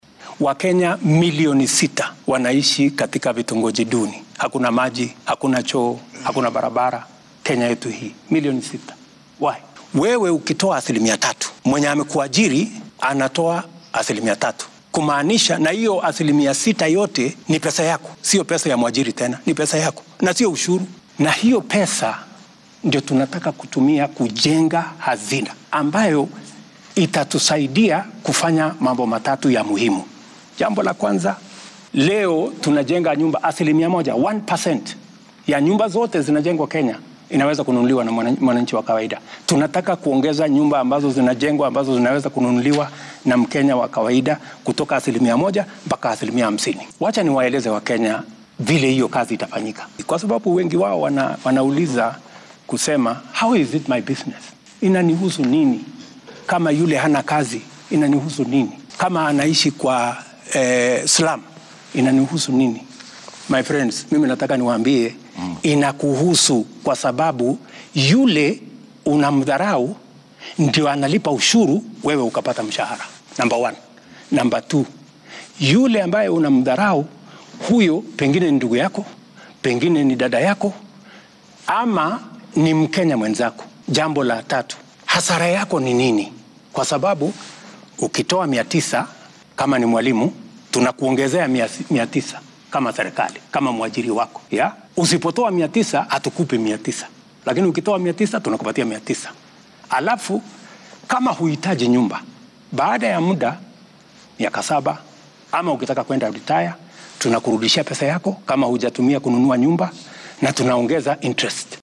Madaxweynaha dalka William Ruto oo xalay fiidkii wareysi wadajir ah siiyay wariyaasha warbaahinno kala duwan ayaa difaacay qorshaha maaliyadeed ee dowladda dhexe gaar ahaan go’aanka lagu soo saaray hindise sharciyeedka Finance Bill 2023.